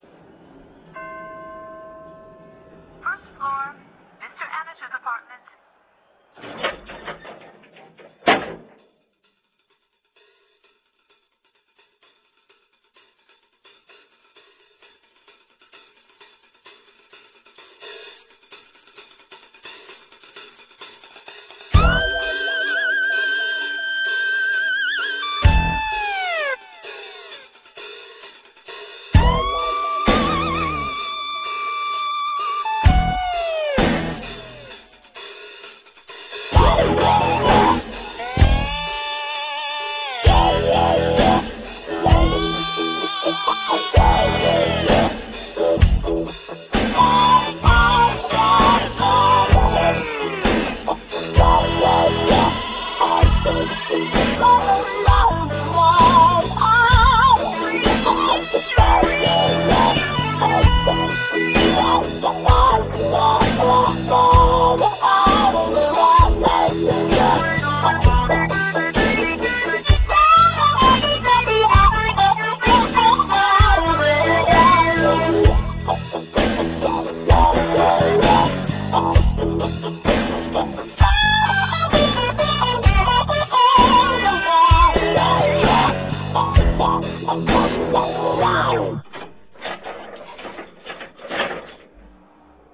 Biblioteca Sonora de Rock Progressivo